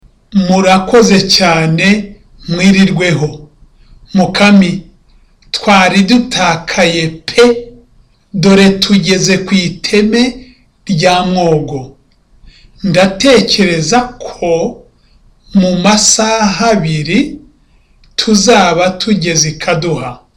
Dialogue: Buranga and Mukamisha on a trip to Kabagari